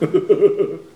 rire_04.wav